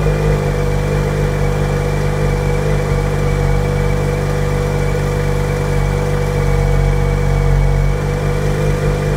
EngineSound.ogg